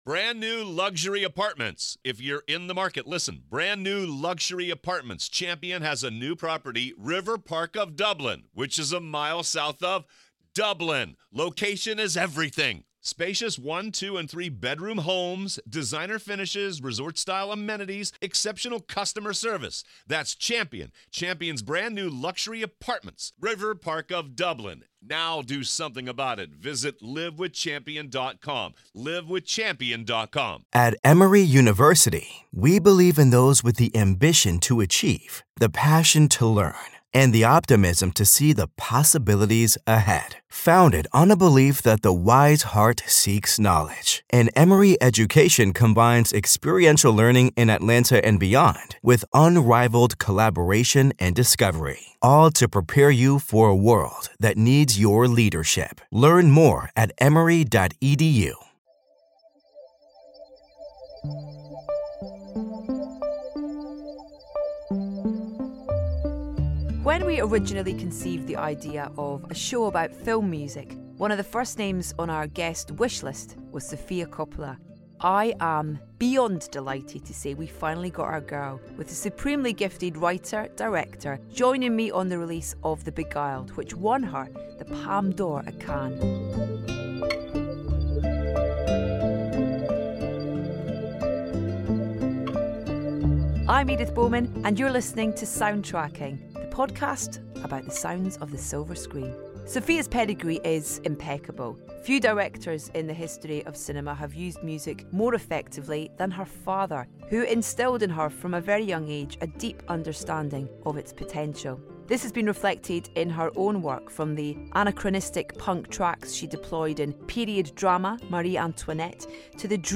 When we originally conceived the idea of a show about film music, one of the first names on our guest wishlist was Sofia Coppola. And we finally got our girl - with the supremely gifted writer / director joining Edith on the release of The Beguiled, which won her the Palme D'Or at Cannes.